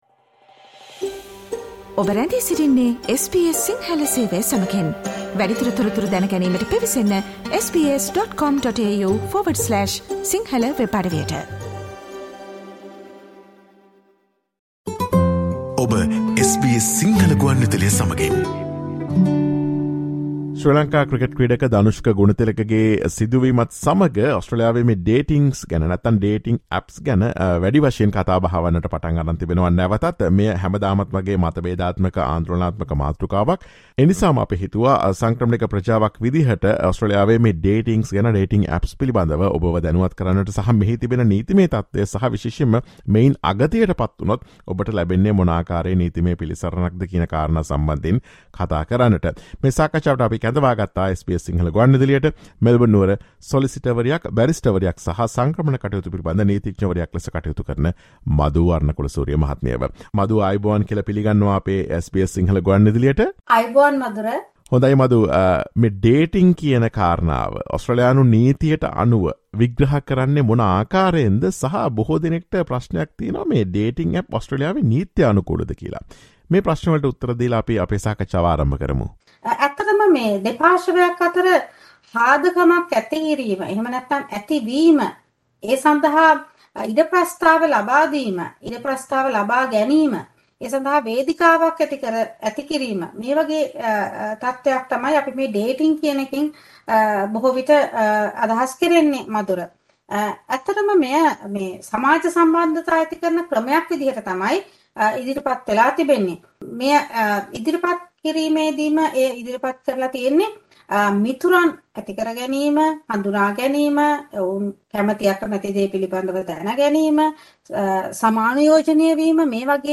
Listen to SBS Sinhala Radio's discussion on the legal status of Dating Apps in Australia and what to consider when using them.